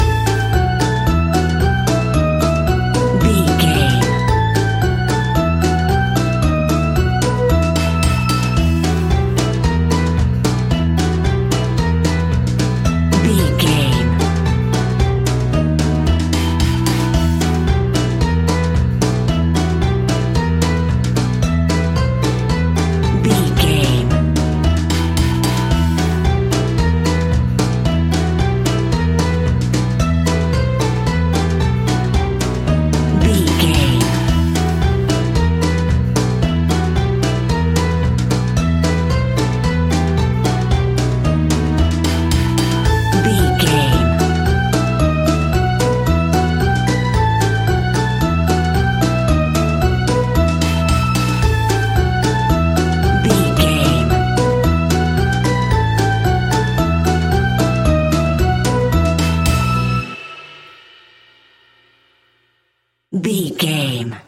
Aeolian/Minor
kids music
fun
childlike
cute
happy
kids piano